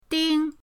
ding1.mp3